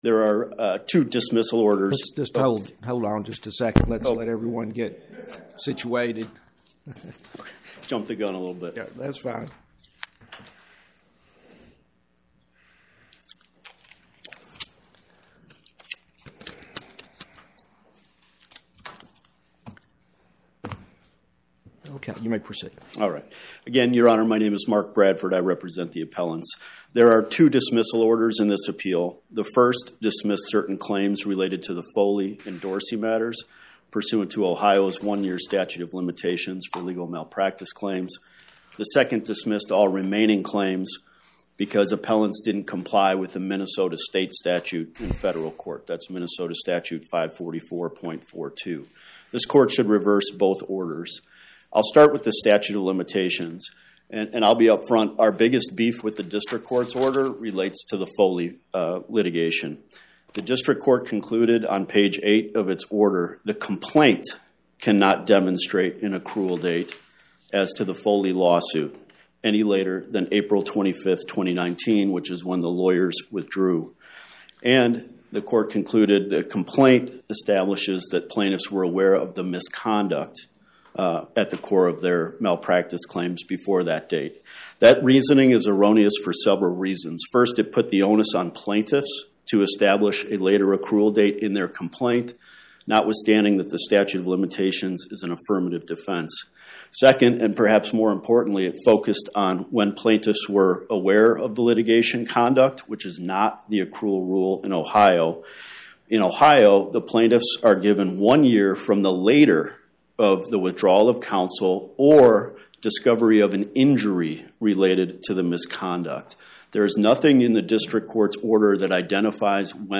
My Sentiment & Notes 25-1950: Everest Stables, Inc. vs Porter, Wright LLP Podcast: Oral Arguments from the Eighth Circuit U.S. Court of Appeals Published On: Thu Mar 19 2026 Description: Oral argument argued before the Eighth Circuit U.S. Court of Appeals on or about 03/19/2026